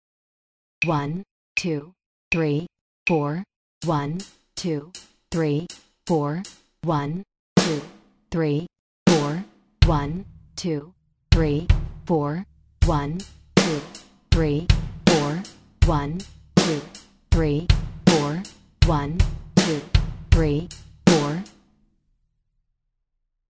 Exemple 3 (exemple de partition de batterie avec trois sources sonnores):
rythme_drums.mp3